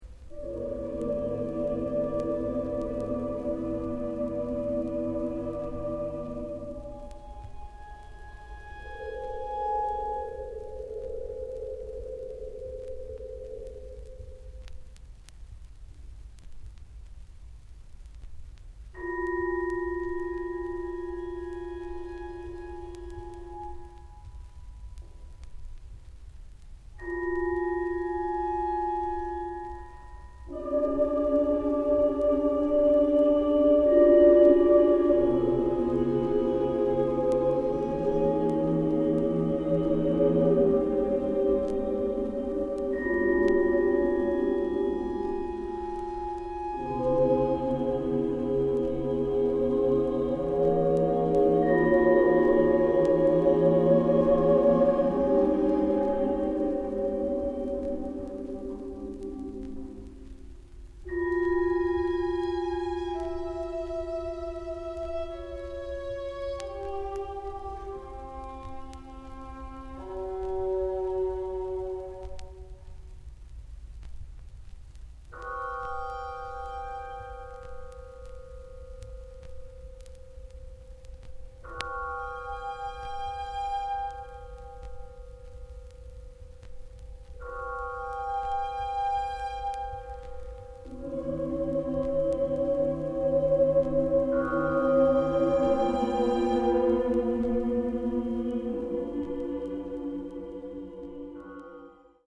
濃密な静寂の気配と揺らめく音色の移ろいに、森厳とした陰影を湛える礼拝堂の空間性が重なる珠玉のサウンドスケープ。
キーワード：　ミニマル　室内楽